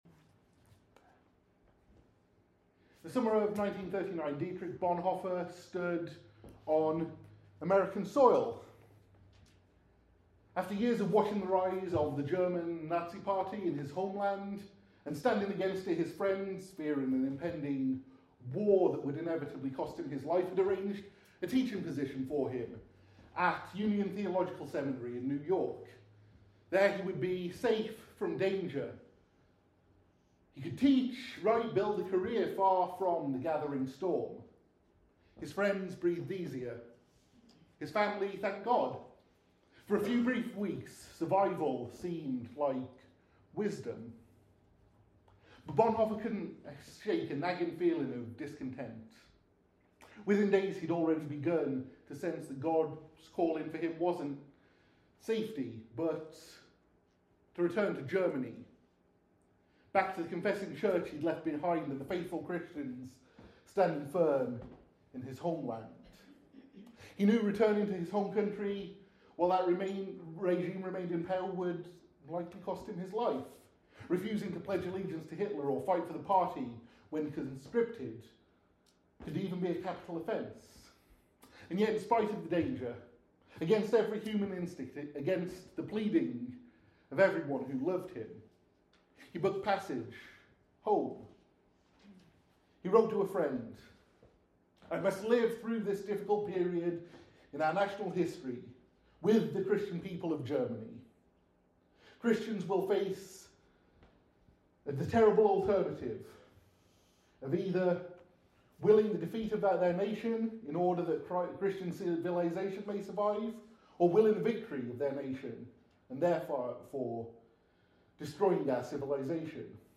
This sermon explores how two ordinary people from Nazareth responded to God’s impossible call with faith and what that teaches us about trusting God’s character when obedience gets costly. Drawing from Luke 1:26-38 and Matthew 1:18-25, this message examines three critical truths about biblical faith.